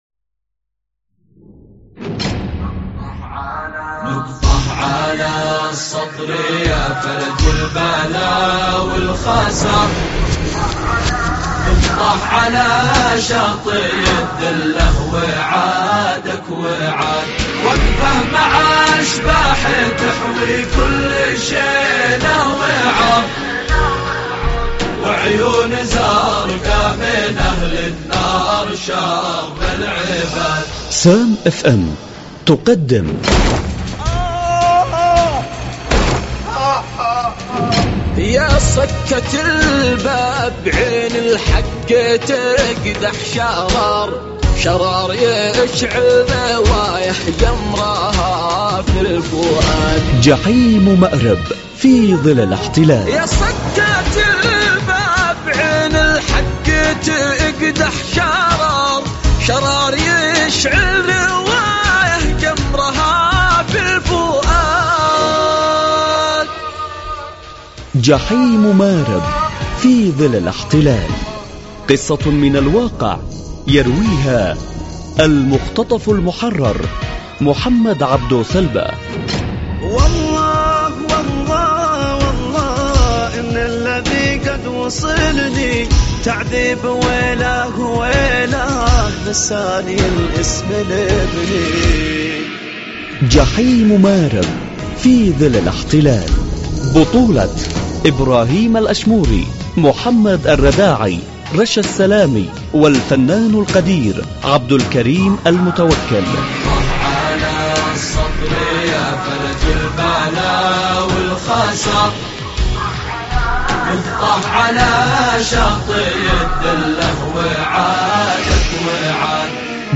المسلسل الإذاعي جحيم مارب في ظل الإحتلال - الحلقة 27